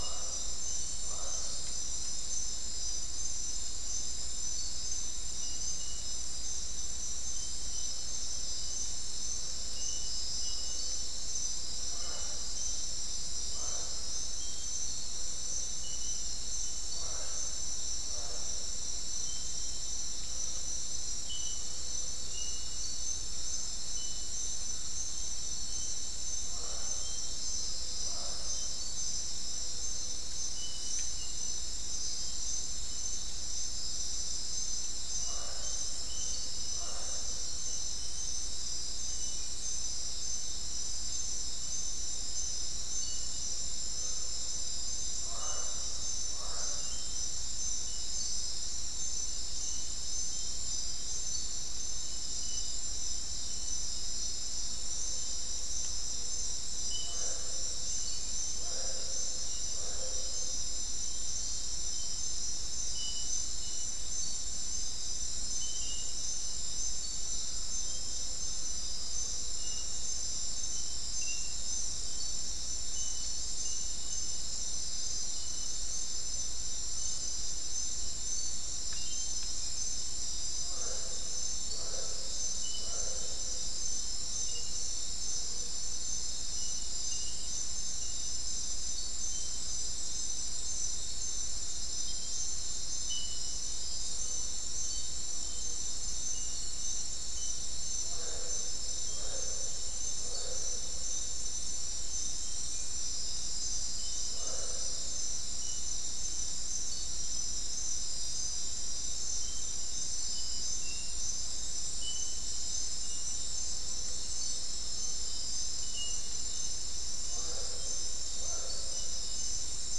Non-specimen recording: Soundscape
Location: South America: Guyana: Mill Site: 4
Recorder: SM3